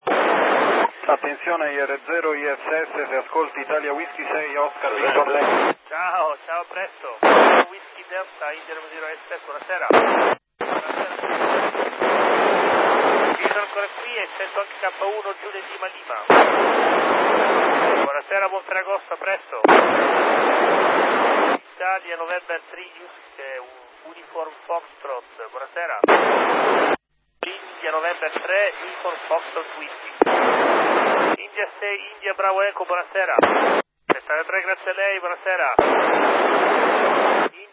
Frequenza RX 145.800 , TX 145.200 (shift -600) Mhz, Mode FM